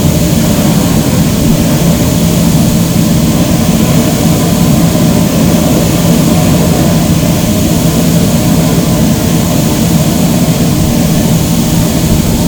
oil-boiler-loop-2.ogg